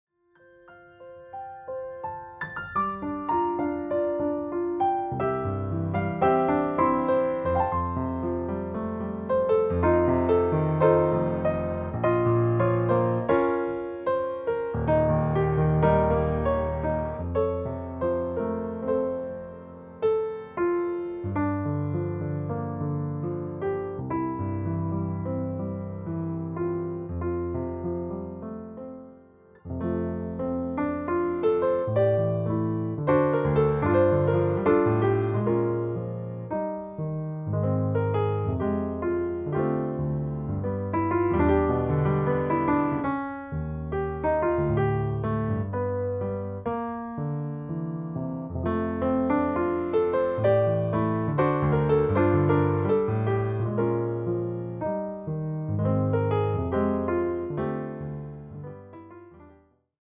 Classical Love Songs Medley